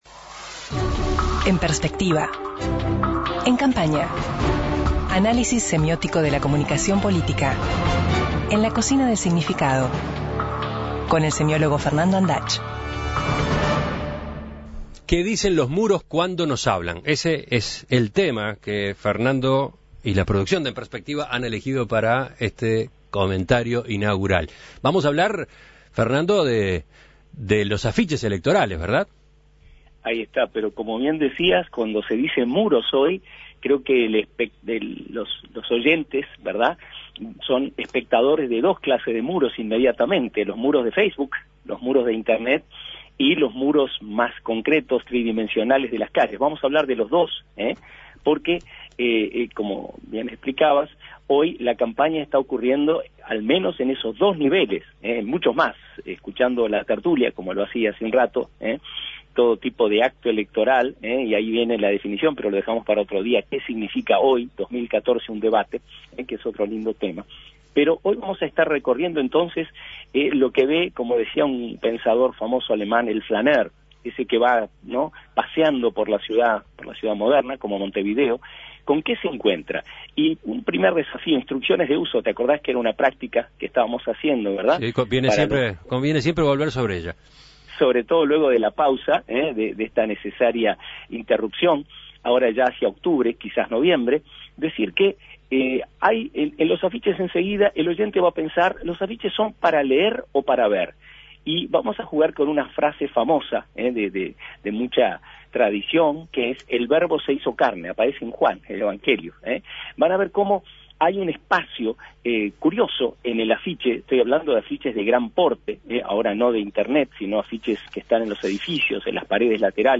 Este ciclo va a ser a distancia, vía Skype.